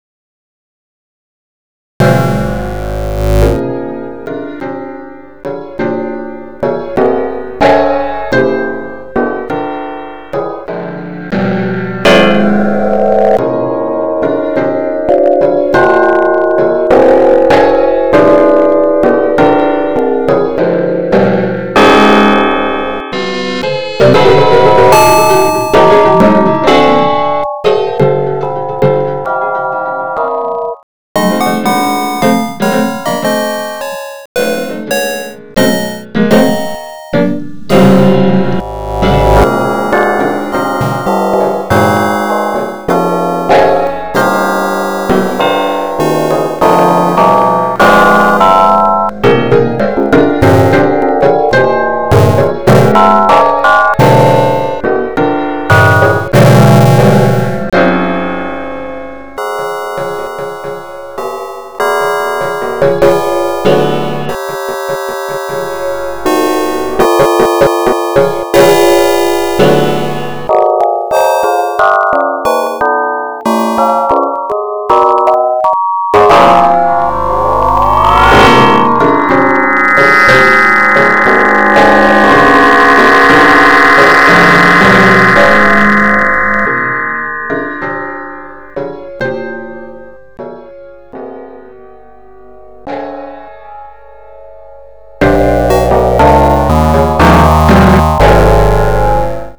Variazione num. 7 su una Mazurca di Chopin (num.8, in La bem. magg., op.7 num.4) per pianoforte, violini e computer  (in contrappunto con frammenti  del "Dies Irae" gregoriano di Tommaso da Celano).